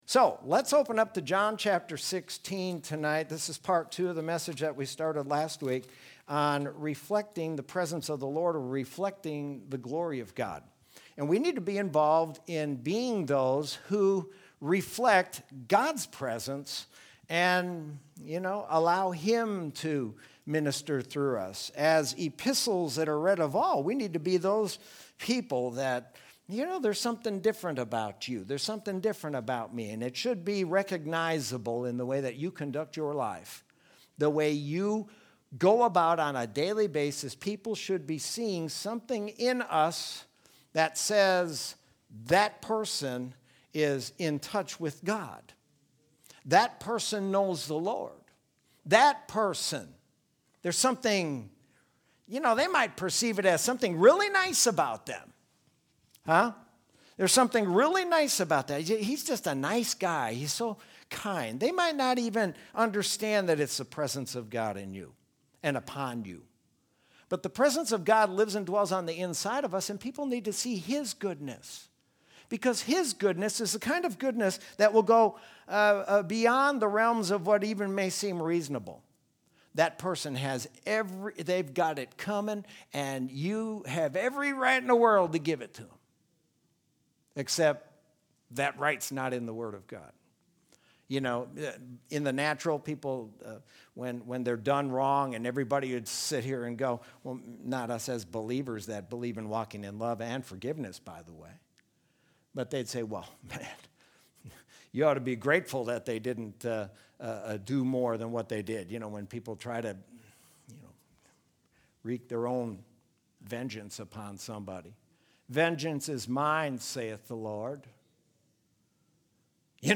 Sermon from Wednesday, August 12th, 2020.